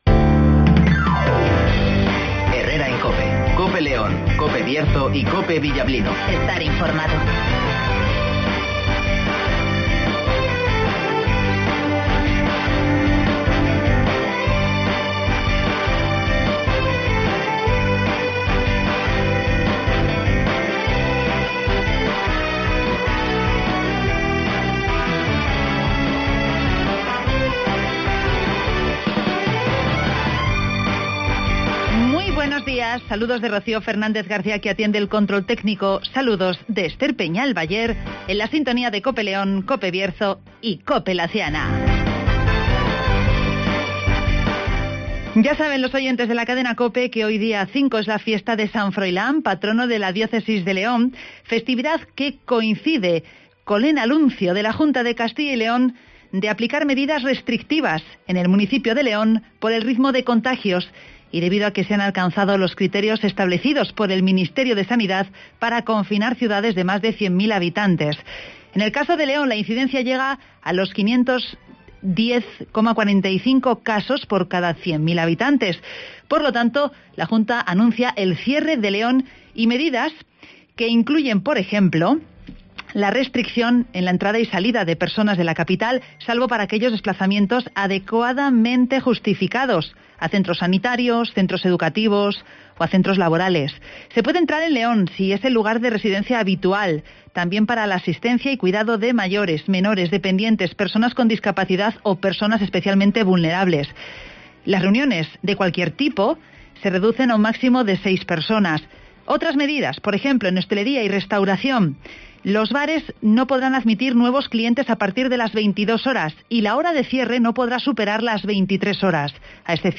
-Avance informativo